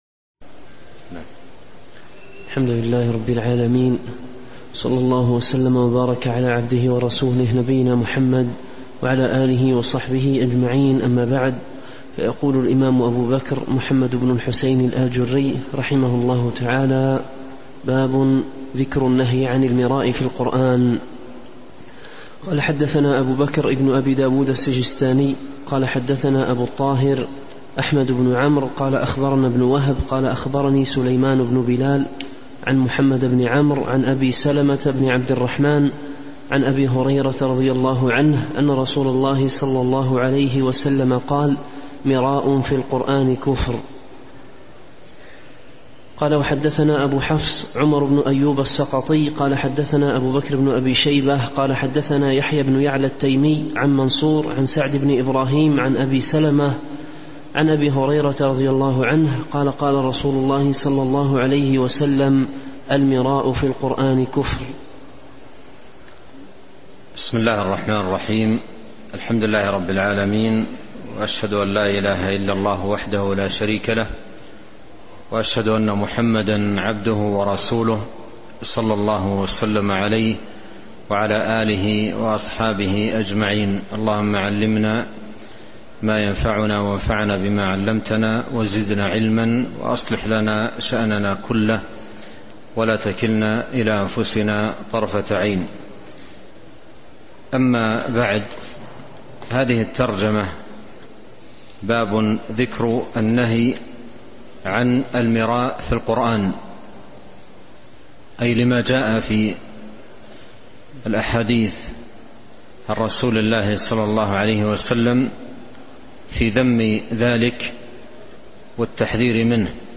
شرح كتاب الشريعة للآجري - 026 باب ذكر النهي عن المراء في القرآن